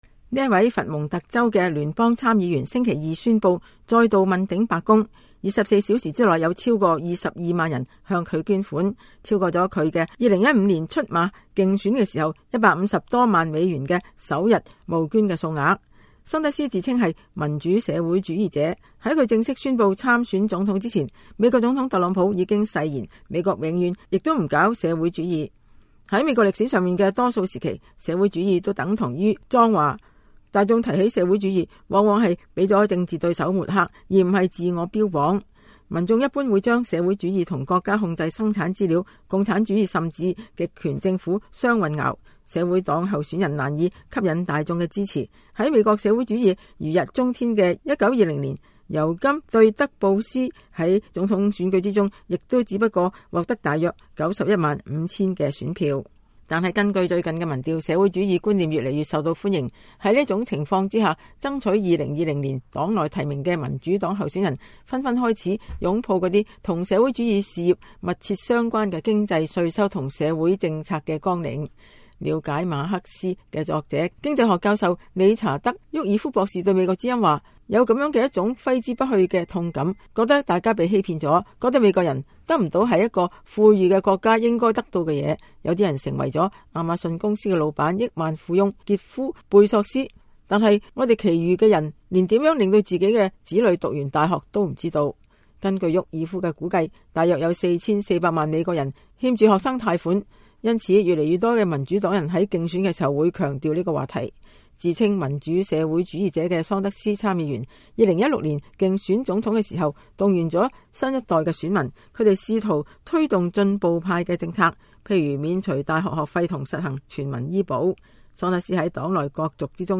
角逐民主黨總統候選人提名的伯尼桑德斯在紐約市關於美國何去何從的講話。